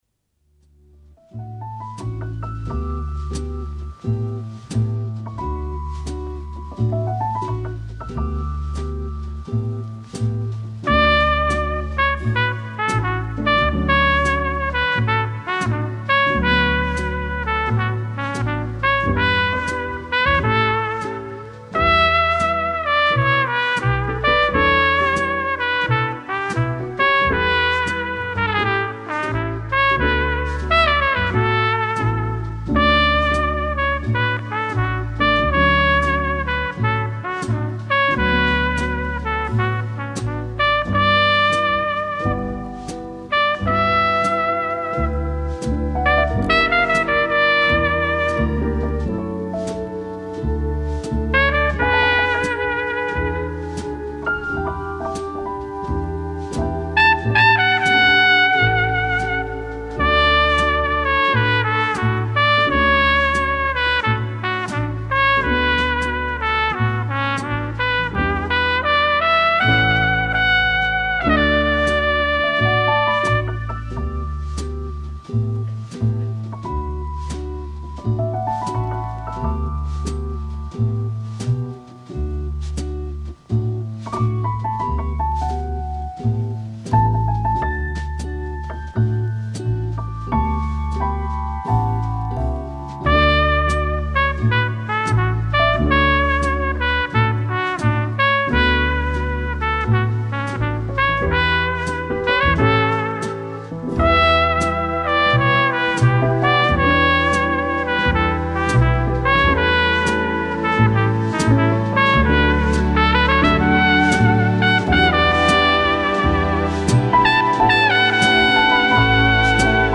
عاشقانه
موسیقی بی کلام ترومپت
جاز موزیک